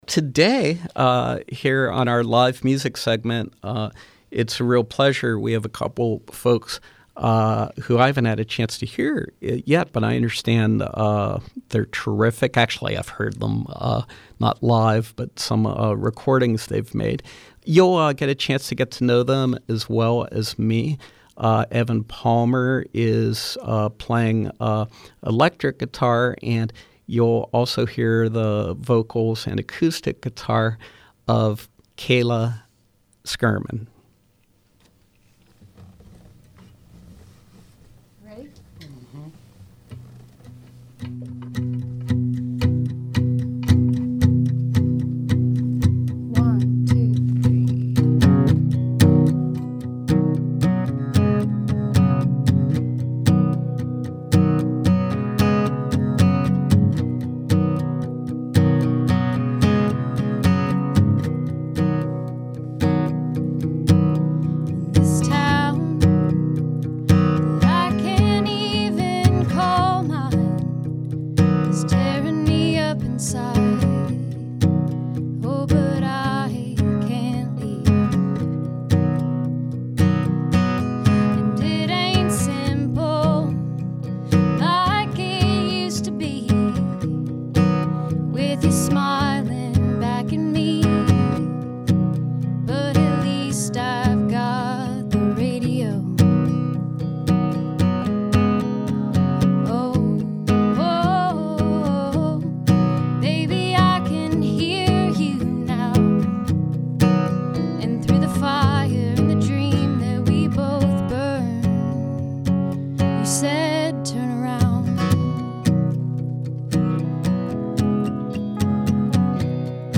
singer-songwriter
guitarist